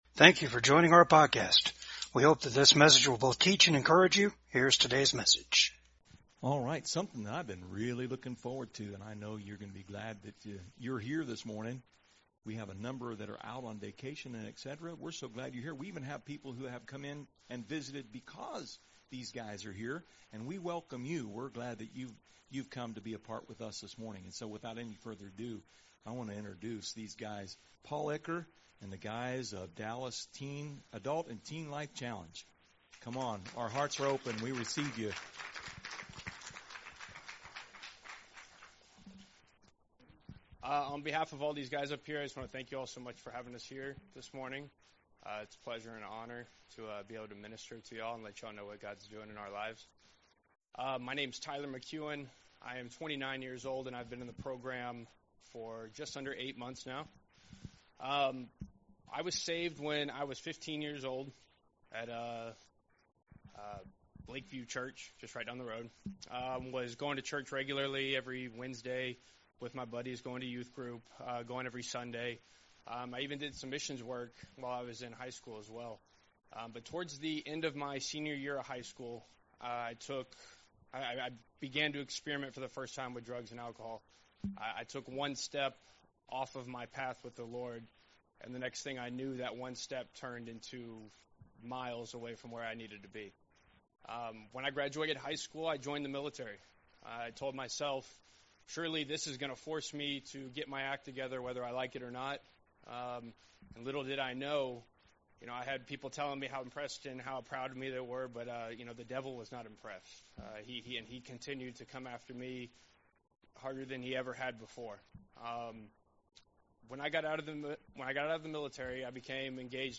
ADULT & TEEN LIFE CHALLENGE DALLAS SERVICE: BE YE STEADFAST